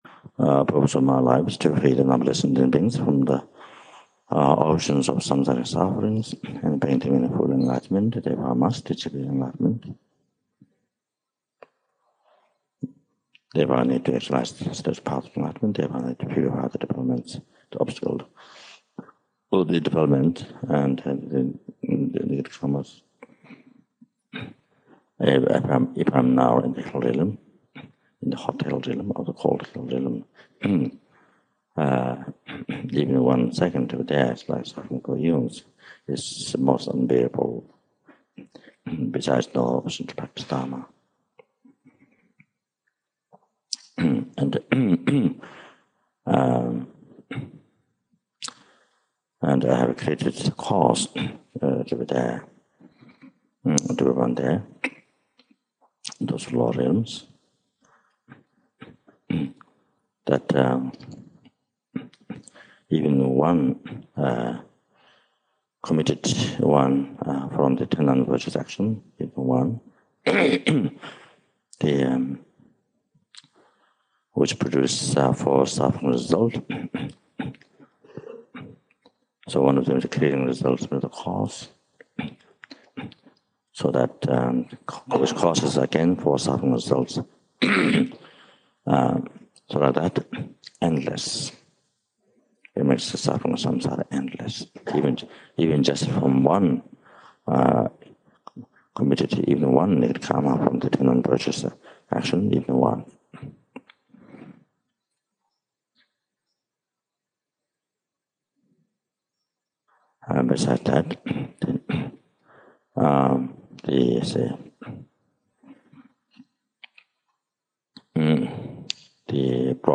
From April 10 to May 10, 2004, Lama Zopa Rinpoche gave extensive teachings during the Mahamudra Retreat at Buddha House in Australia.
Lama Zopa Rinpoche reads the General Confession in the presence of two relics.
Rinpoche then recites the increasing effect mantra, the mala blessing mantra, and the mantra for blessing the feet.